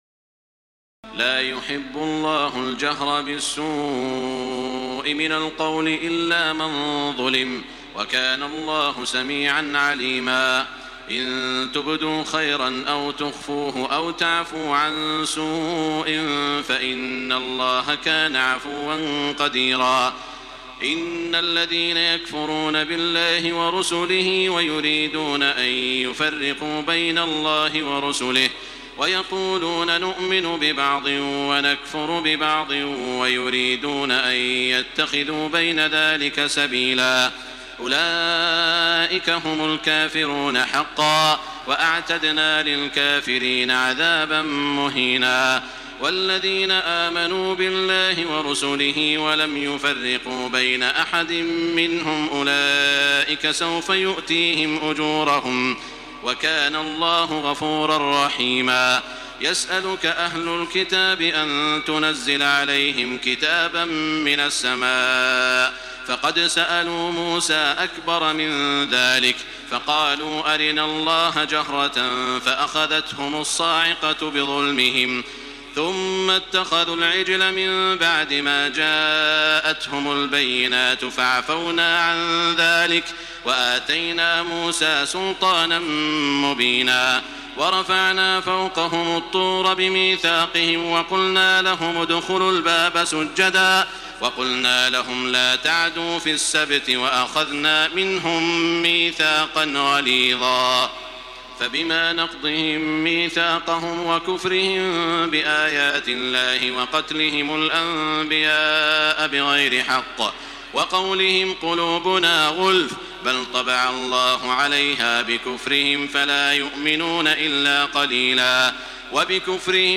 تراويح الليلة السادسة رمضان 1428هـ من سورتي النساء (148-176) و المائدة (1-26) Taraweeh 6 st night Ramadan 1428H from Surah An-Nisaa and AlMa'idah > تراويح الحرم المكي عام 1428 🕋 > التراويح - تلاوات الحرمين